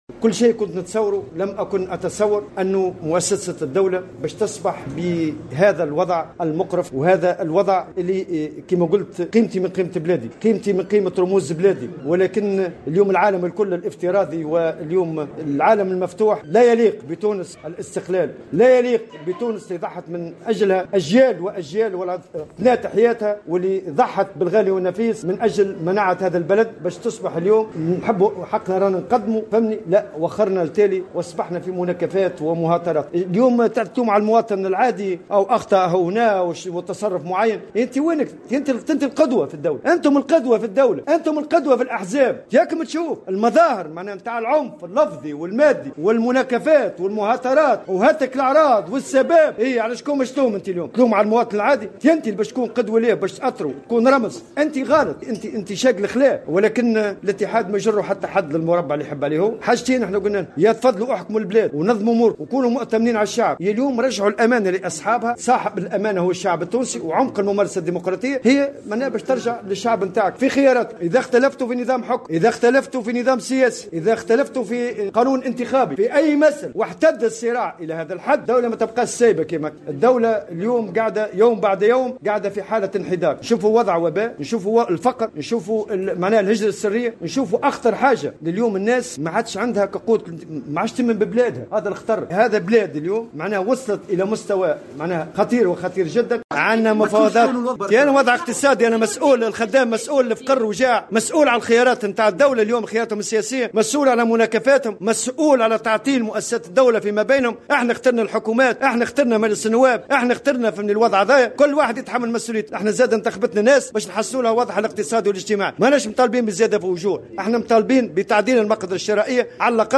قال الأمين العام للاتحاد العام التونسي للشغل نور الدين الطبوبي، في تصريح لمراسل الجوهرة أف أم، اليوم السبت 26 جوان 2021، إن على السياسيين التوقف عن "المناكفات والمهاترات" وتحمل مسؤولياتهم في قيادة البلاد، أو أن يعيدوا الأمانة إلى الشعب، عبر إجراء انتخابات مبكرة.
وأكد الطبوبي على هامش تدشين مقر الاتحاد الجهوي للشغل بالمنستير، أن الدولة تعيش يوما بعد يوم، حالة من الانحدار على جل الأصعدة، سواء الاجتماعية والاقتصادية أو الصحية، واصفا الوضع في البلاد بـ"المقرف"، في ظل استمرار الأزمة السياسية.